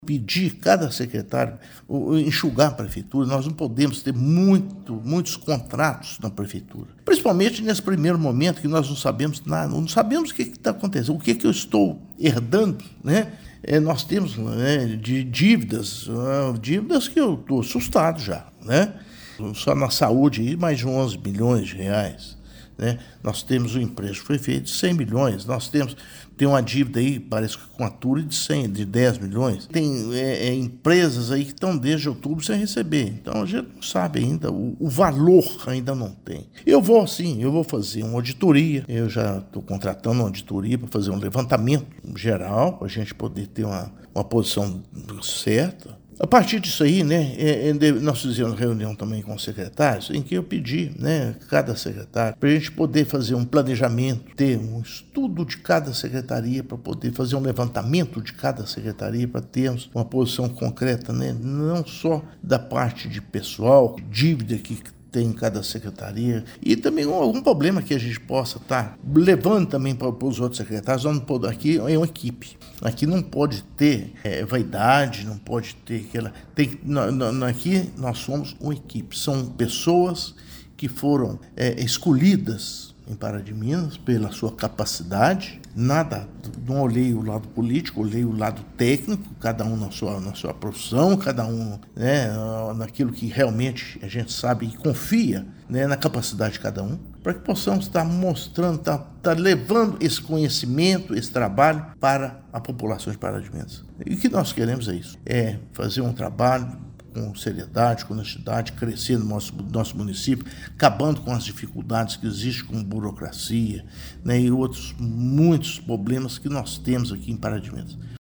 Na sequência, Inácio Franco se reuniu com seu secretariado e também cobrou empenho de todos e muito cuidado com os contratos. Afirmou que ainda não sabe o que está herdando e nem o tamanho da dívida de Pará de Minas.